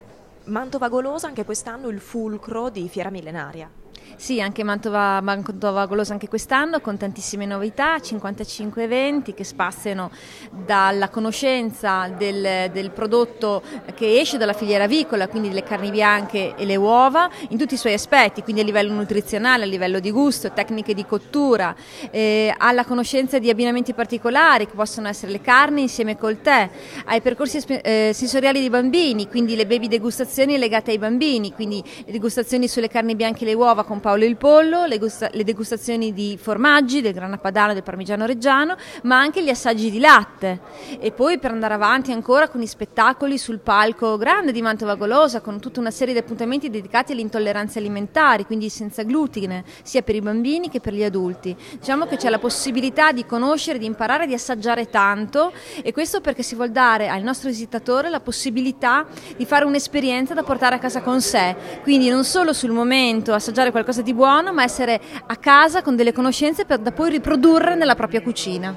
Conferenza stampa di presentazione della Fiera Millenaria di Gonzaga, edizione 2019